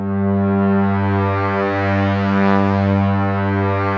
P.5 G#3 7.wav